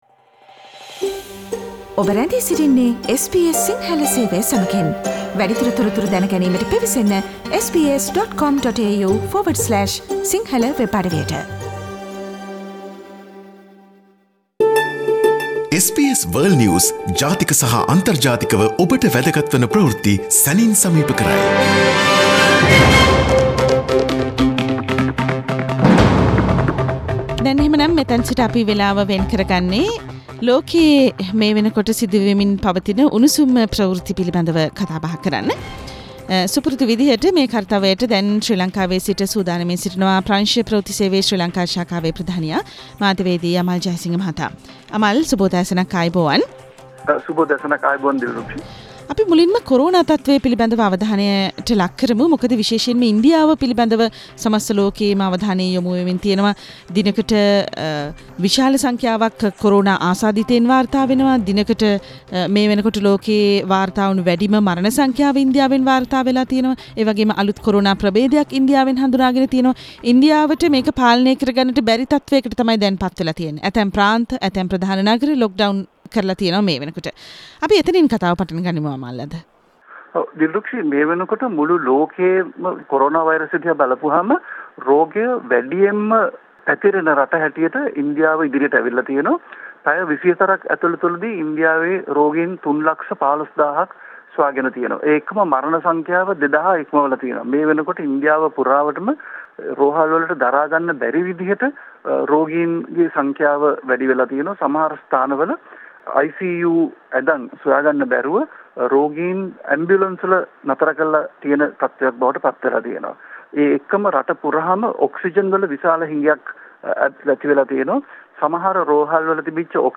Listen to the latest news from around the world this week from our weekly "Around the World" foreign news review.